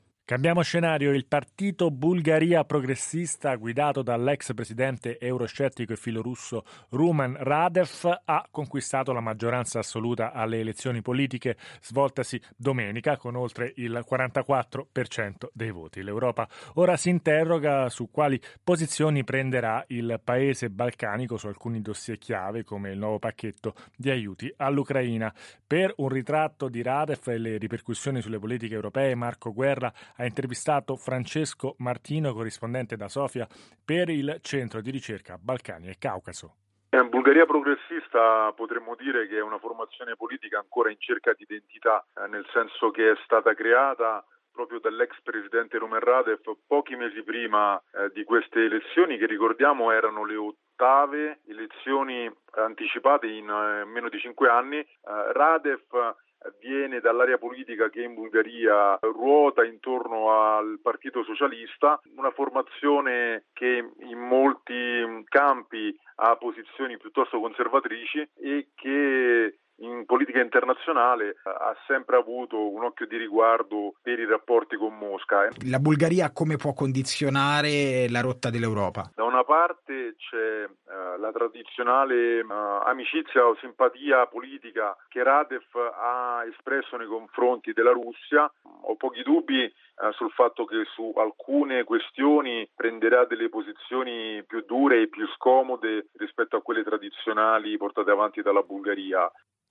Alle elezioni di domenica 19 aprile Rumen Radev, con “Bulgaria progressista”, ha ottenuto in una vittoria schiacciante. Quanto le sue posizioni nei confronti di Mosca, potranno avere peso in Unione europea? Il commento